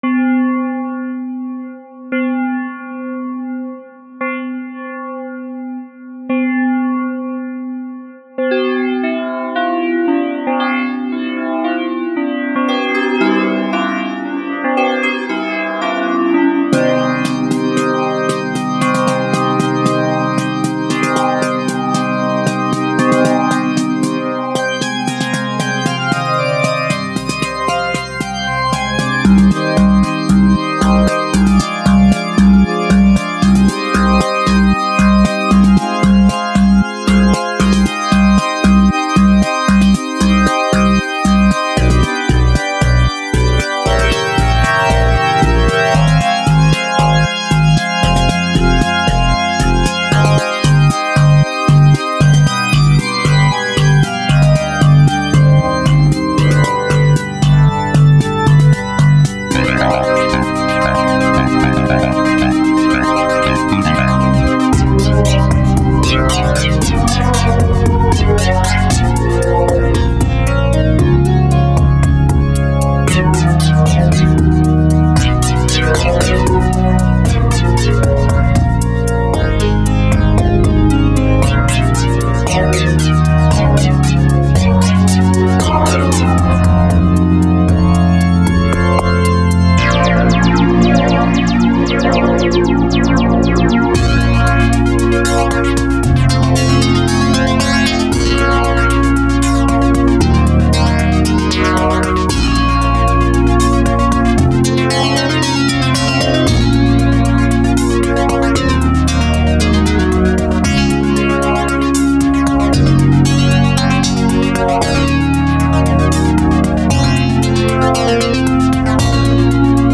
Электронная музыка. Space, Ambient, New Age, Ethereal